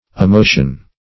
amotion - definition of amotion - synonyms, pronunciation, spelling from Free Dictionary
Amotion \A*mo"tion\, n. [L. amotio. See Amove.]